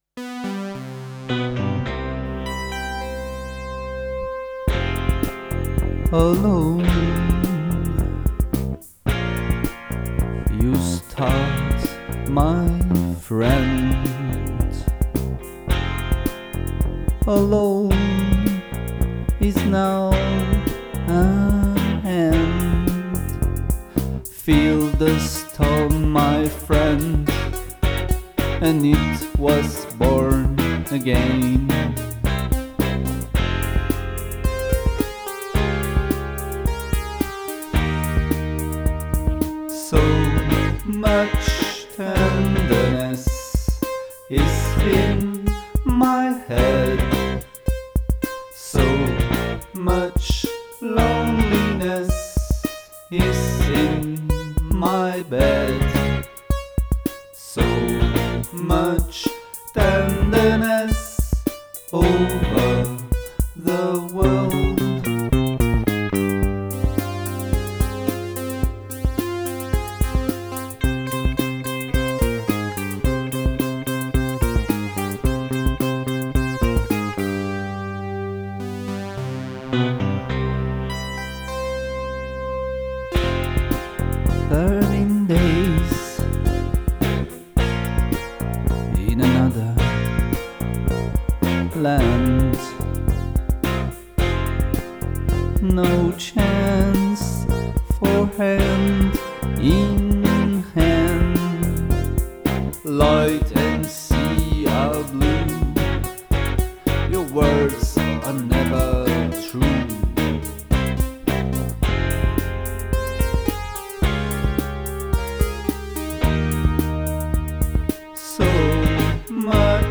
Voici, pour fêter notre cinéma préféré, une version studio du morceau. Nous l’avons enregistré à trois.
la basse et la guitare
les synthés